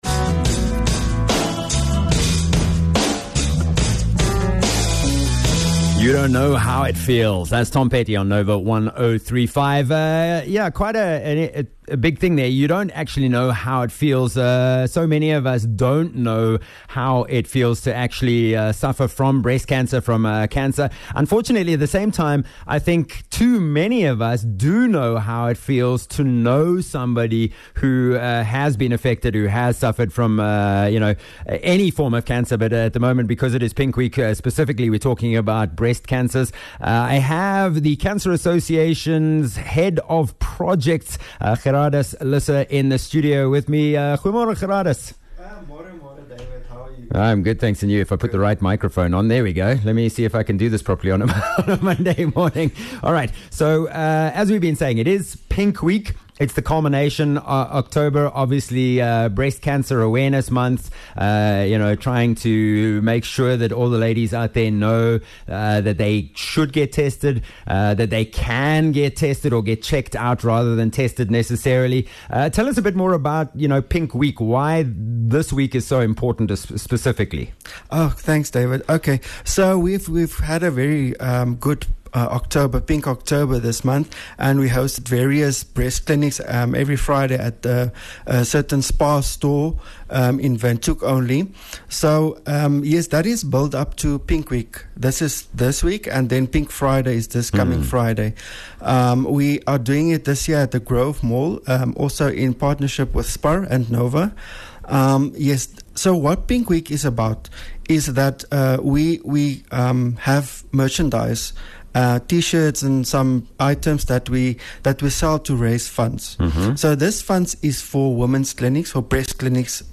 21 Oct Pink Week Interview